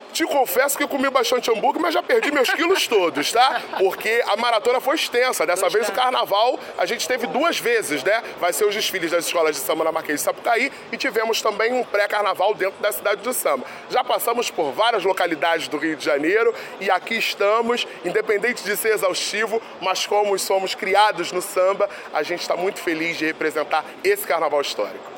Cerimônia de entrega das chaves foi realizada no jardim do Palácio da Cidade